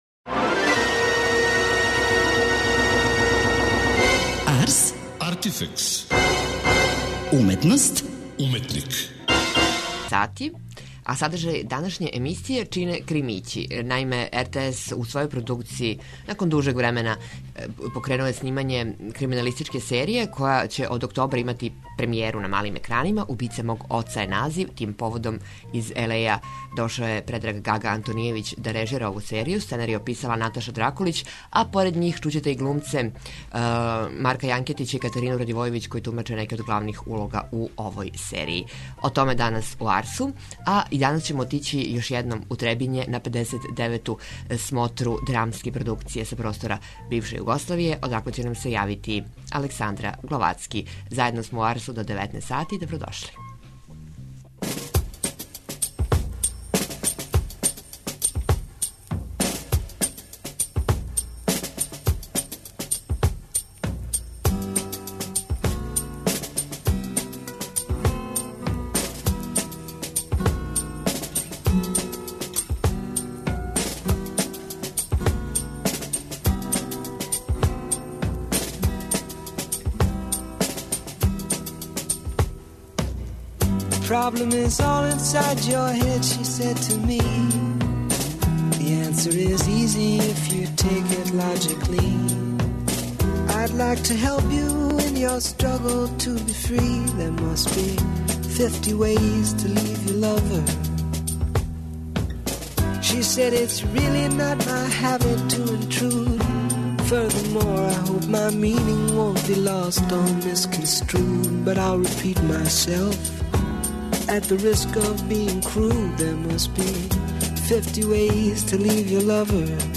Поред њих у емисији ћете чути и глумце из серије, чија се радња се дешава у савременом Београду, а прати младог, али већ искусног инспектора, који добија први велики случај - решавање убиства ћерке јединице угледног грађевинског предузимача. Поред ове приче, у емисији вам доносимо и преглед дешавања на 59. Фестивалу драмских аматерских позоришта који траје у Требињу.